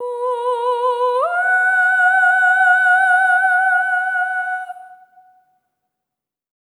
SOP5TH B4 -R.wav